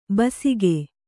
♪ basige